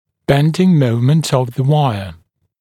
[‘bendɪŋ ‘məumənt əv ðə ‘waɪə][‘бэндин ‘моумэнт ов зэ ‘уайэ]изгибающий момент дуги